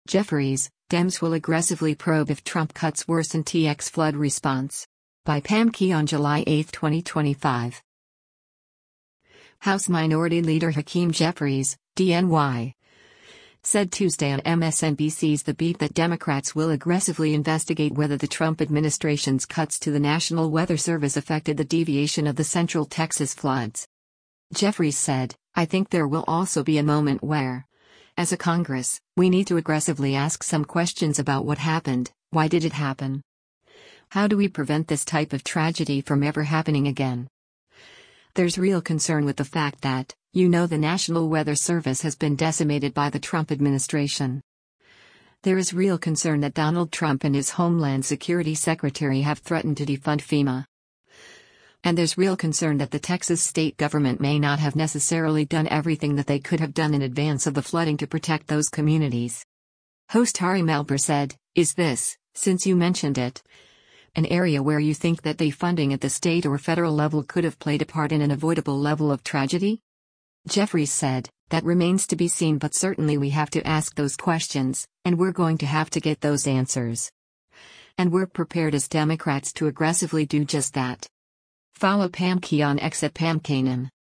House Minority Leader Hakeem Jeffries (D-NY) said Tuesday on MSNBC’s “The Beat” that Democrats will “aggressively” investigate whether the Trump administration’s cuts to the National Weather Service affected the deviation of the central Texas floods.
Host Ari Melber said, “Is this, since you mentioned it, an area where you think that defunding at the state or federal level could have played a part in an avoidable level of tragedy?”